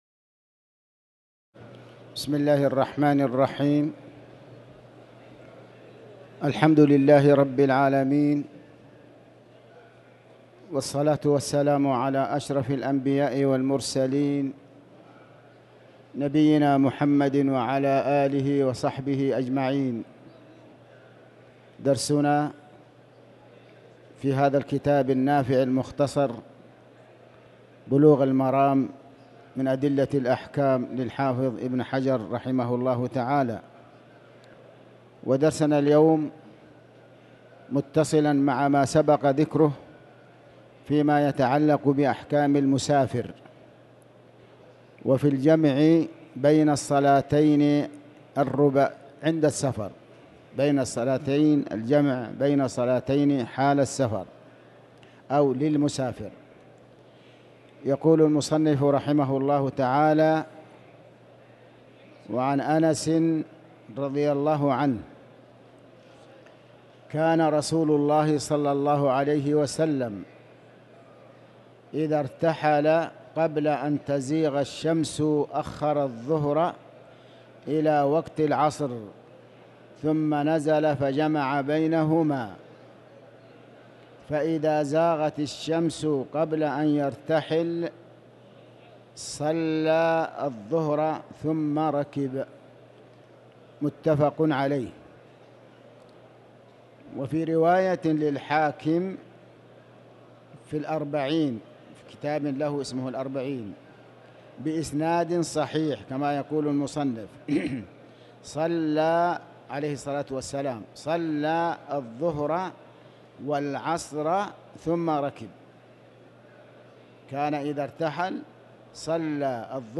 تاريخ النشر ٢٢ ذو القعدة ١٤٤٠ هـ المكان: المسجد الحرام الشيخ